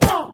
Sound / Minecraft / mob / witch / hurt1.ogg
should be correct audio levels.
hurt1.ogg